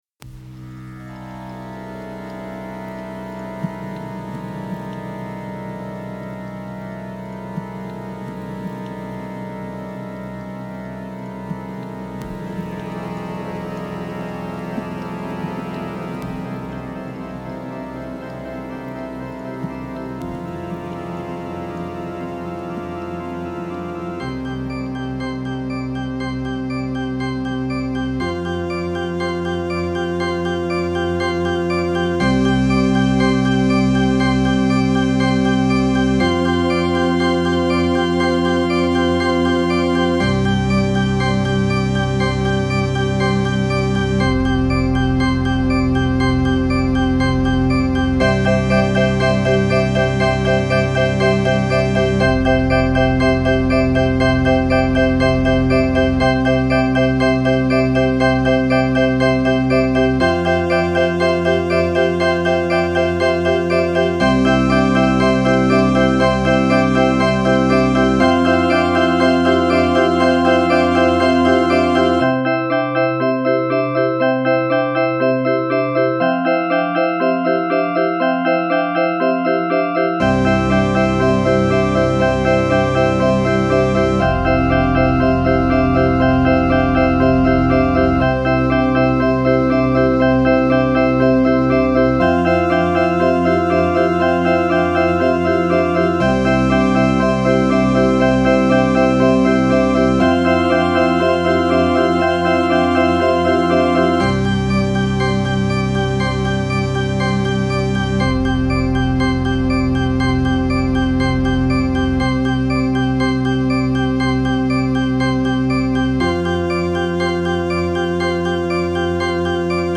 So, I took my MacBook armed with an M-Audio MobilePre USB downstairs, hooked into the organ’s phono plug, installed the latest version of Audacity and started recording.
So far, I’ve only gone through about 2 minutes worth and made 3 SoundFont patches for myself, one being the ‘background noise’ you can almost hear while the tubes are warming up, which I amplified.
I wrote a weird little track using ONLY the samples that I’ve created, and some reverb.
Wurlitzer_4150_Fun.mp3